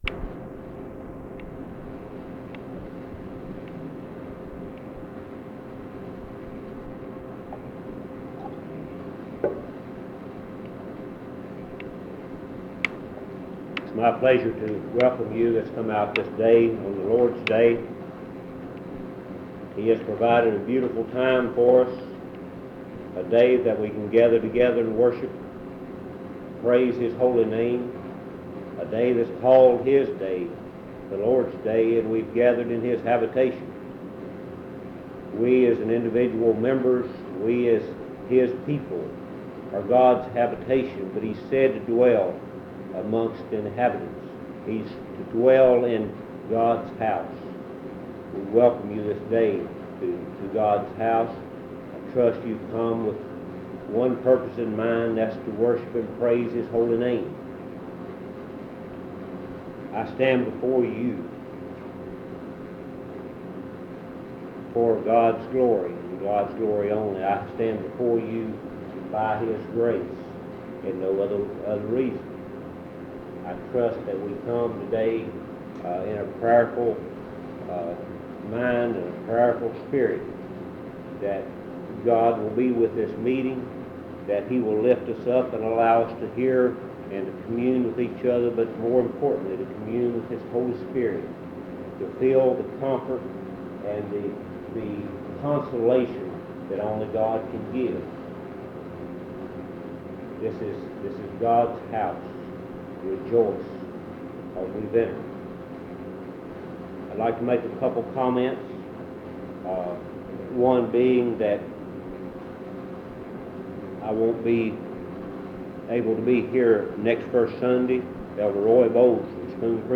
Em Collection: Monticello Primitive Baptist Church audio recordings Miniatura Título Data de carga Acesso Ações PBHLA-ACC.002_006-A-01.wav 2026-02-12 Baixar PBHLA-ACC.002_006-B-01.wav 2026-02-12 Baixar